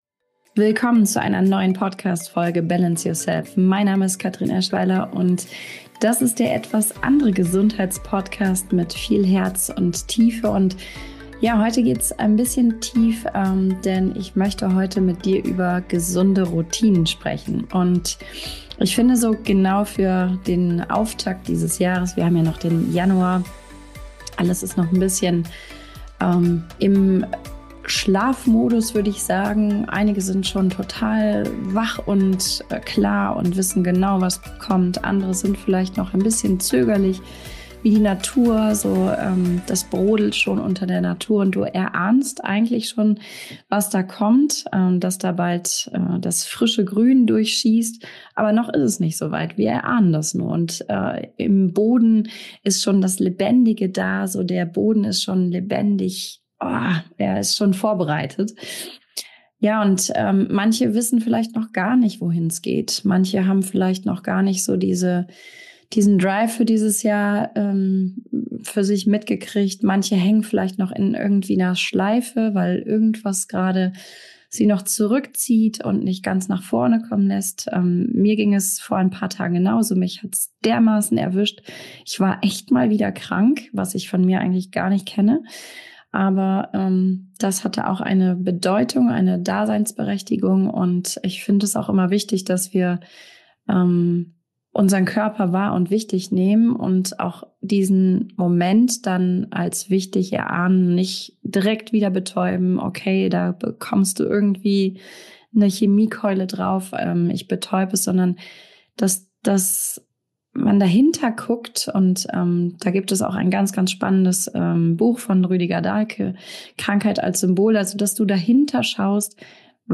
Solofolge